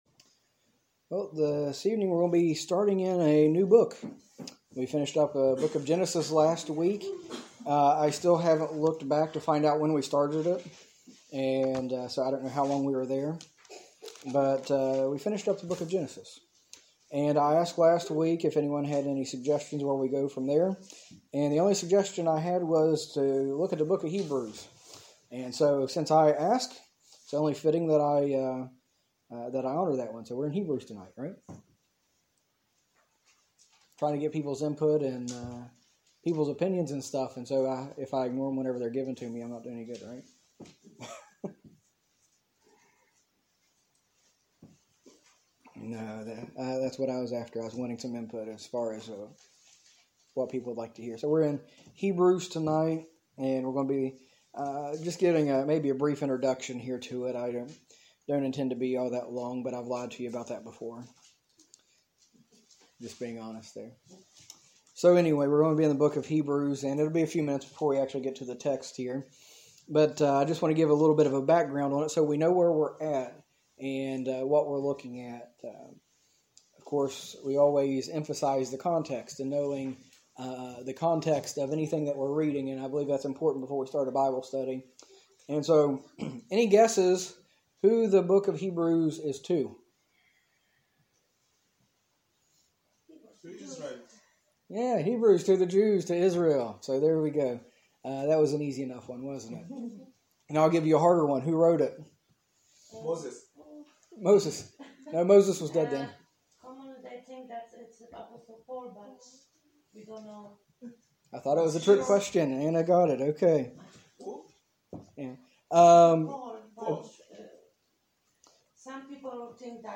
Sermons - Longford Baptist Church